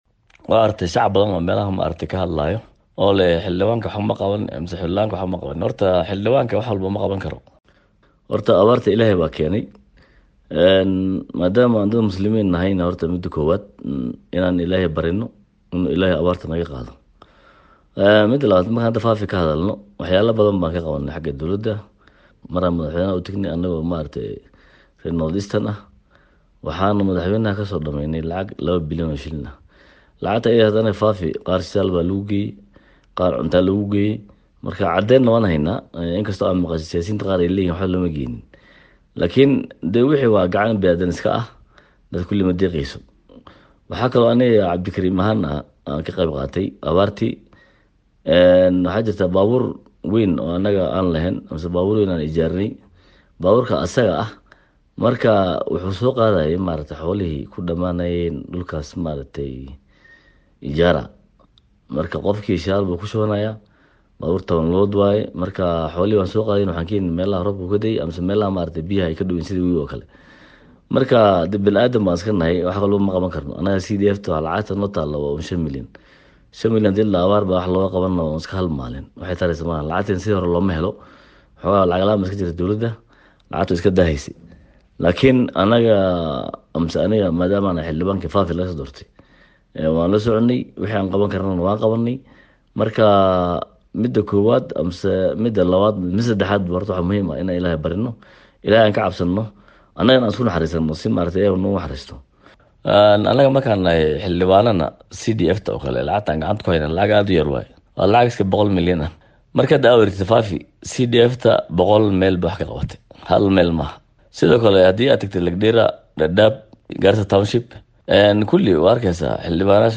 Xildhibaanka laga soo doortay kursiga baarlamanka e FAFI Cabdikarim Cismaan ayaa difaacay doorka xildhibaanada Barlamaka ay ka qaateen la tacaalida abaarti samaynta adag ku yeelatay gobolka Waqoyi bari. Xildhibanka o la hadlay idaacada STAR FM ayaa hadaladiisa waxa kamid ahaa.